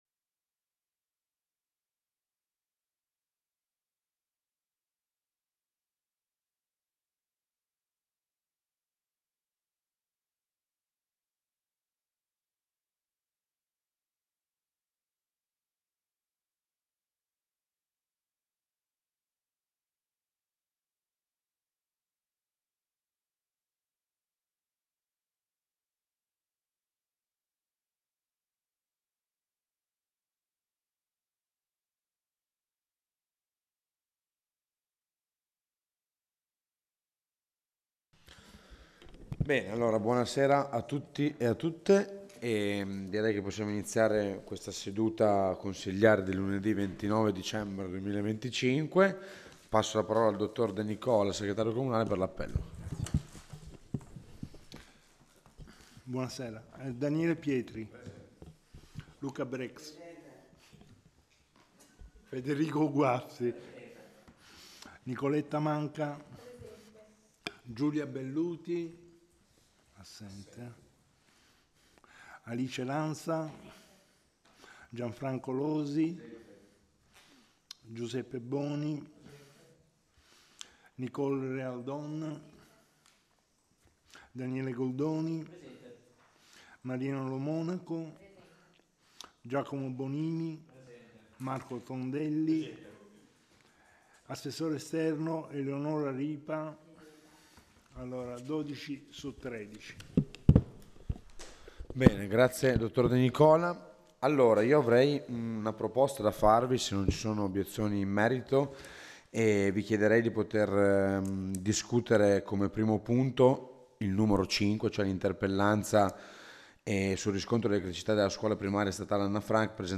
Di seguito sono accessibili le registrazioni audio ed i relativi verbali di approvazione dei consigli comunali, a partire dall'anno 2025: